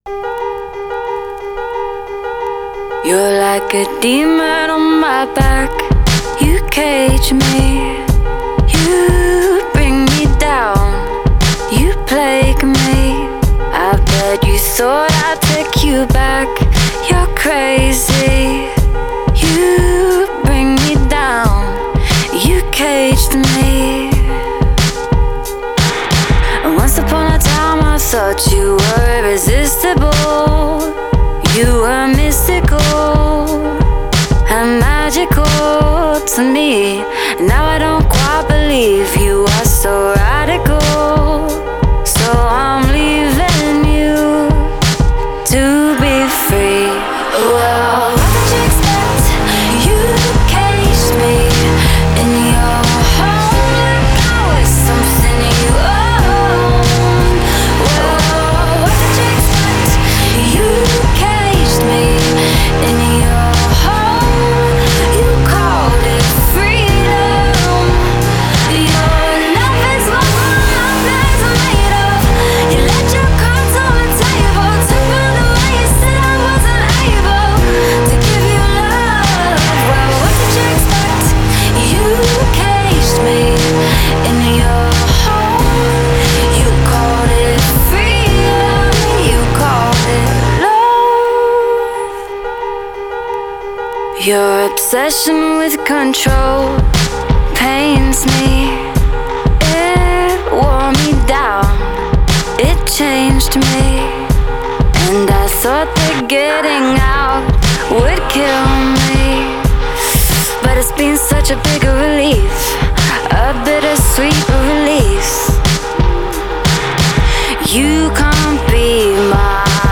Genre: Pop, Singer-Songwriter